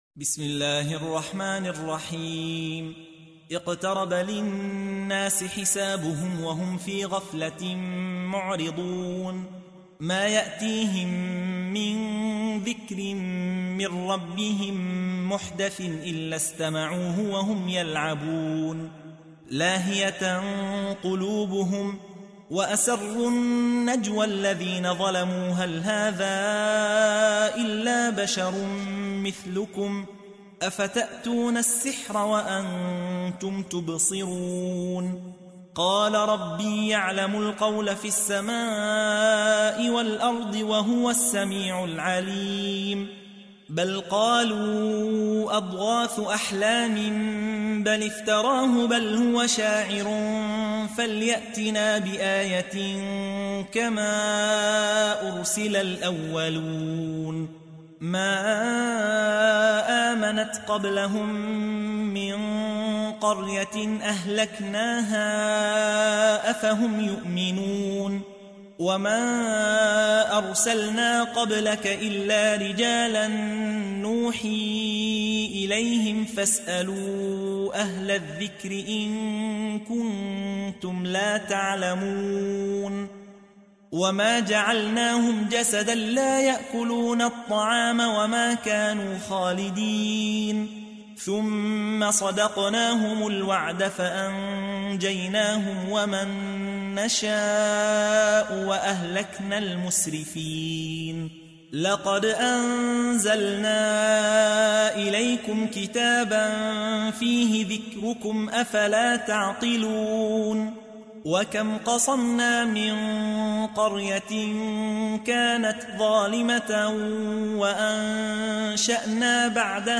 تحميل : 21. سورة الأنبياء / القارئ يحيى حوا / القرآن الكريم / موقع يا حسين